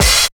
137 OP HAT.wav